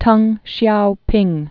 (tŭng shyoupĭng, dŭng)